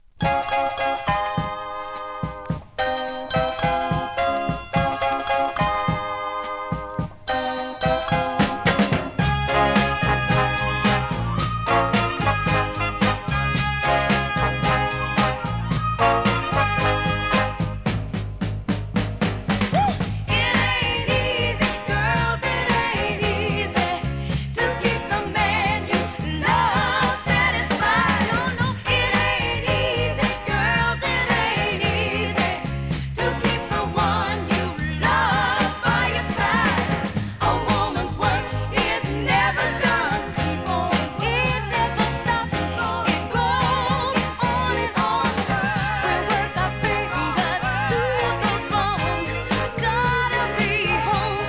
店舗 ただいま品切れ中です お気に入りに追加 1969年ノーザン・ソウル!